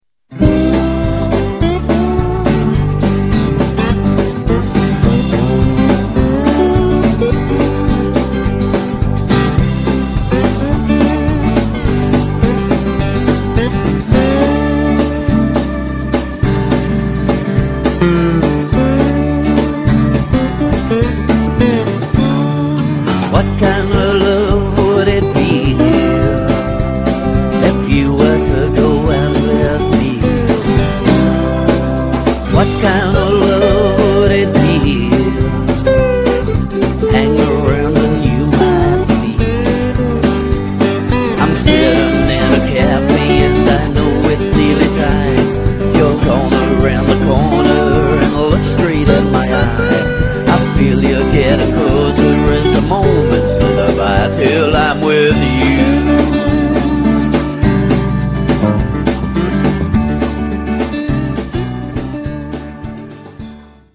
Intro.-C-D-G-C-G x 3 - D (rundown)